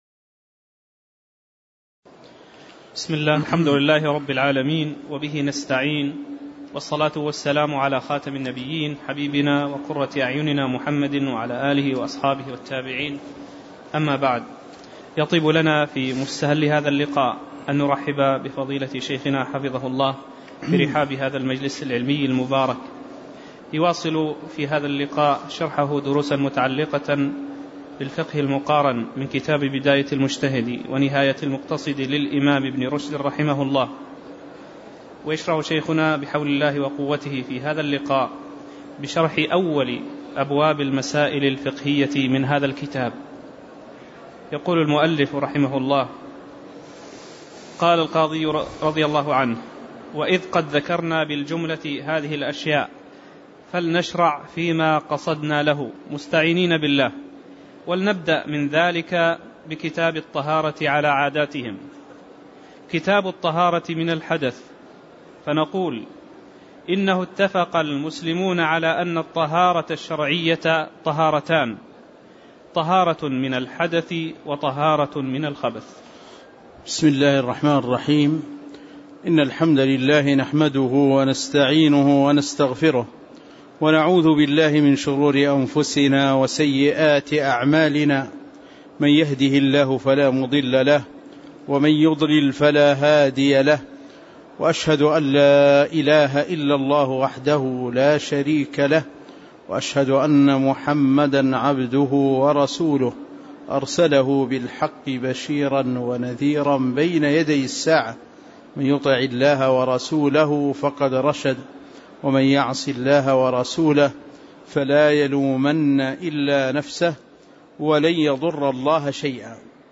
تاريخ النشر ١٦ جمادى الأولى ١٤٣٩ هـ المكان: المسجد النبوي الشيخ